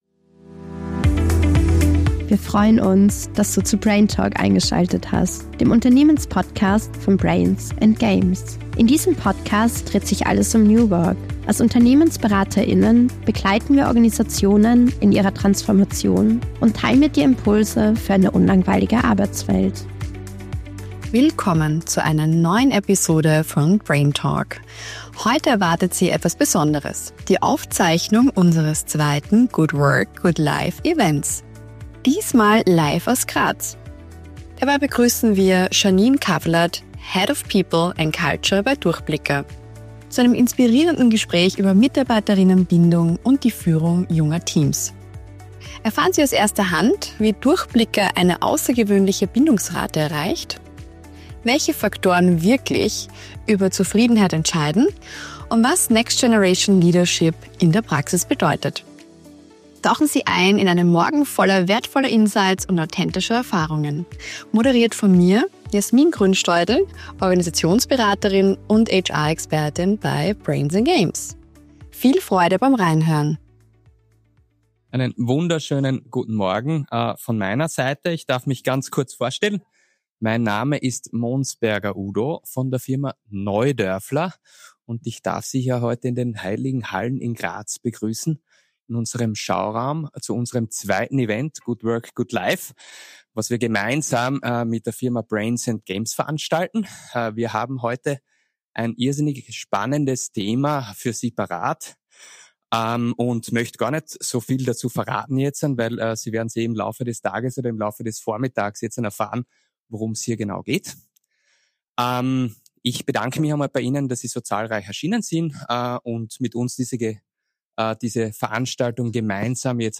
Good Work Good Life #2: Der Schlüssel zu nachhaltiger Mitarbeiter:innenbindung (Liveaufzeichnung aus Graz) ~ BRAINTALK MIT - BRAINS AND GAMES Podcast